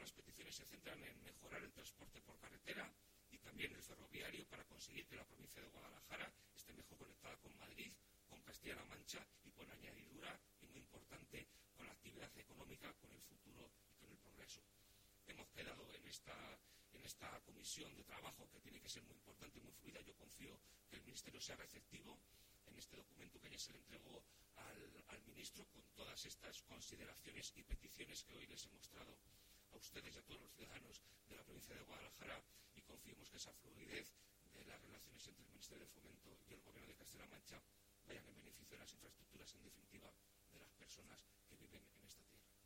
El delegado en Guadalajara habla de las peticiones realizadas por el presidente al ministro de Fomento para la provincia